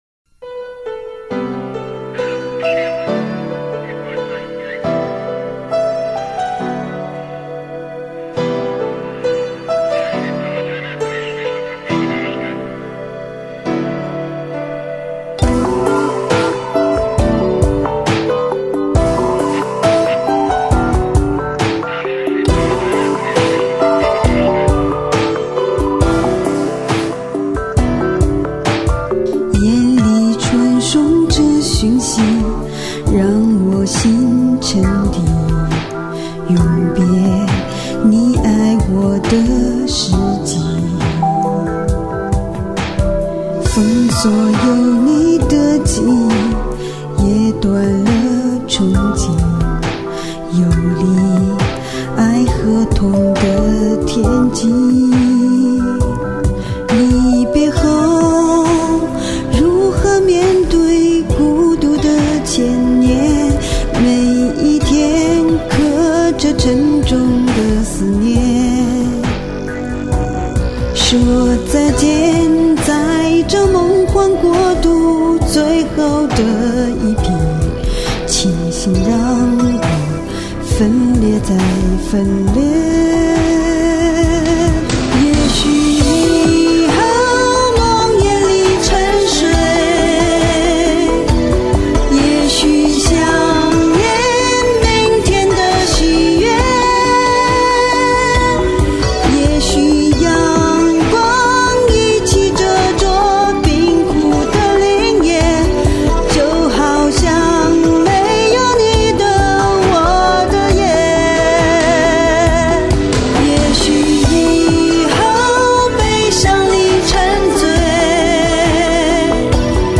很羡慕你的powerful的嗓音，觉得需要宣泄的地方都很淋漓尽致的感觉~BTW,颤音也很好噢，我为啥点头也不能颤？
太震撼了。特别是后半部分。powerful ! 唱功突飞猛进的感觉。好好好。
唱得好有感觉，寂寞，狂野，颓废，嘎嘎嘎。。。。